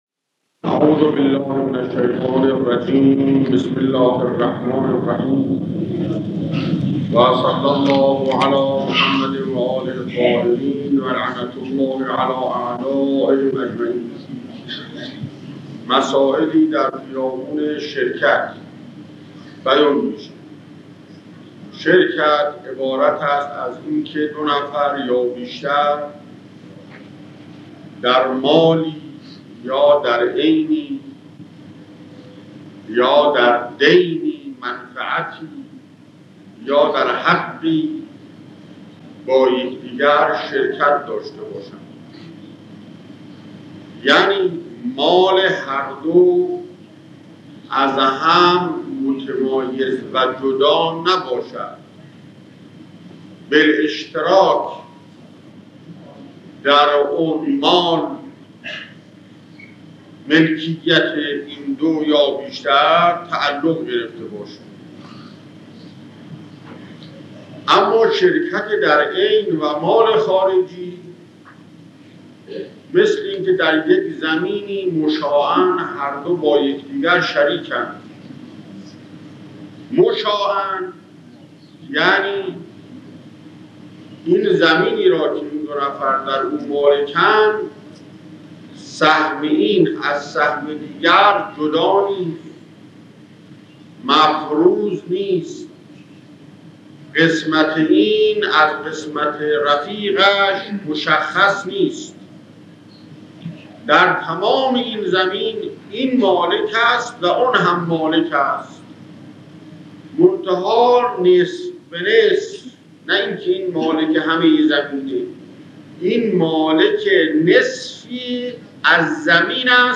سخنران علامه آیت‌اللَه سید محمدحسین حسینی طهرانی